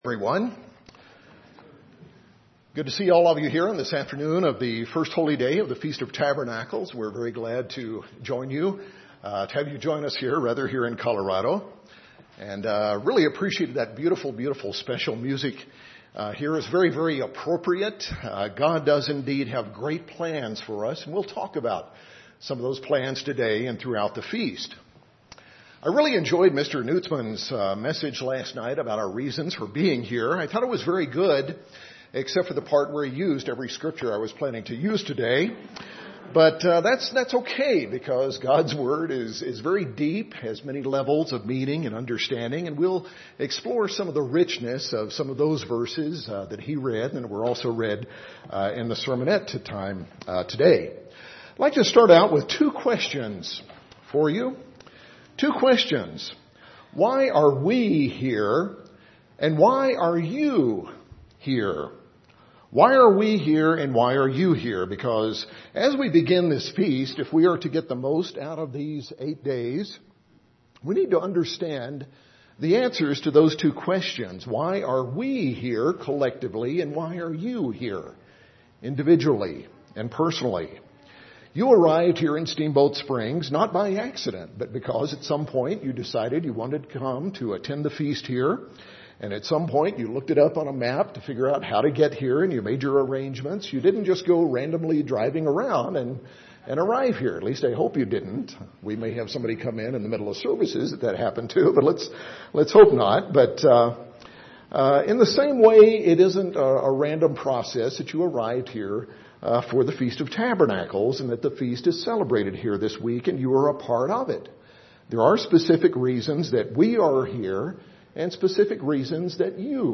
This sermon was given at the Steamboat Springs, Colorado 2014 Feast site.